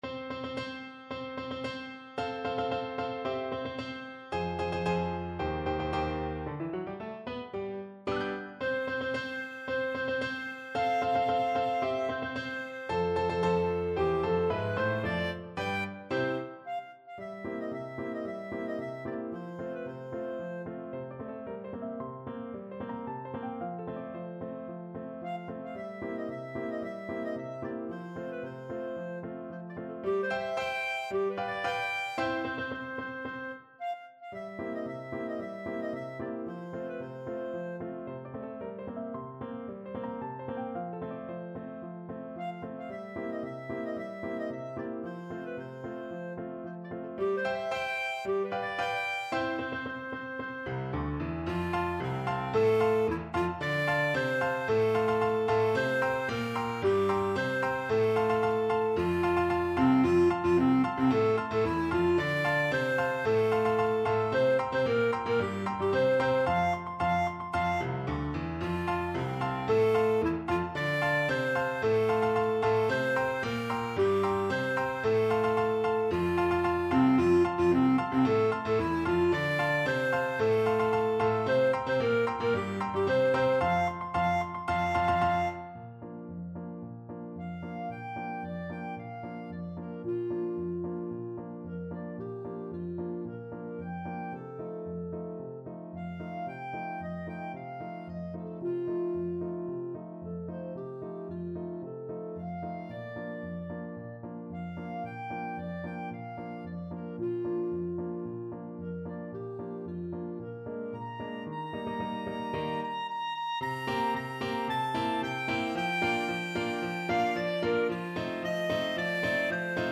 Clarinet
2/4 (View more 2/4 Music)
F major (Sounding Pitch) G major (Clarinet in Bb) (View more F major Music for Clarinet )
~ = 112 Introduction
Classical (View more Classical Clarinet Music)